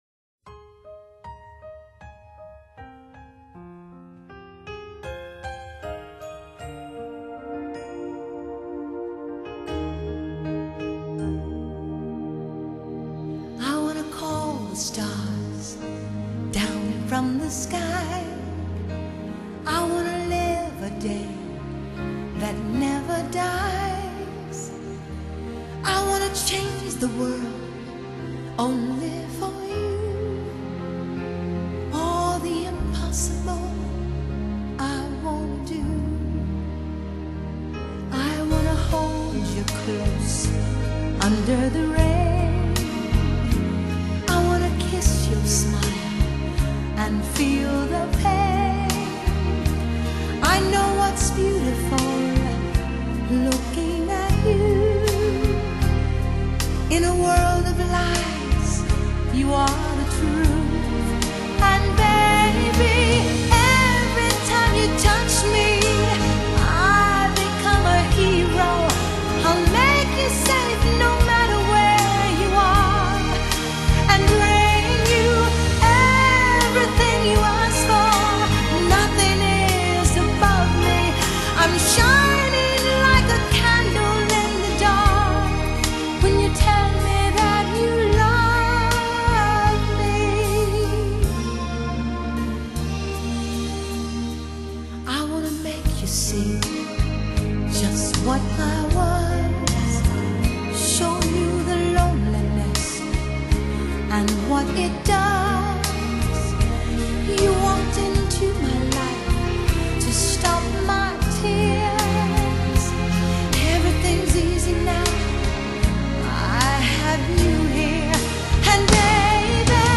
MP3 | 320 KBPS+booklet | Pop |1996 |197 MB